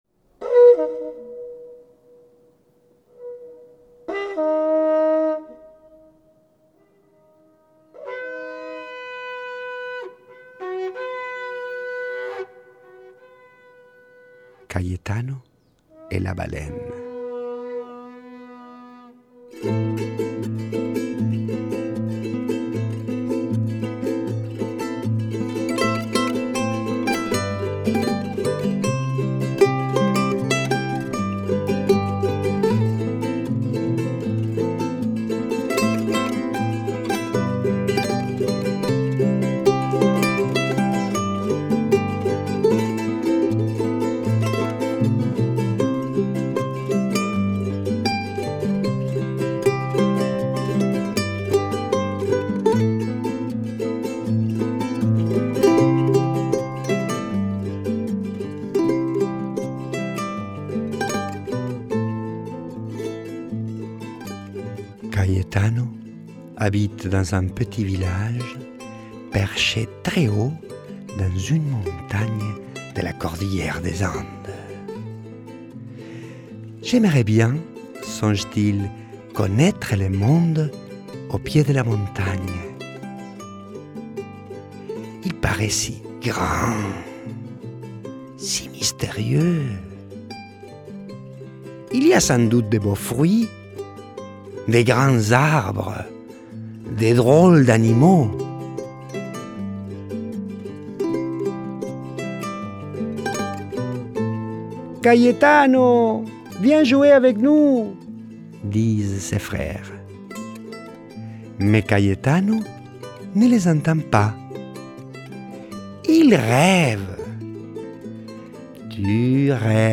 La musique sud-américaine
Flûtes andines (ocarina, quena, tarkas, moxeño, toyo, erke)
Charango
Saxophone
Guitare
Accordéon et bandonéon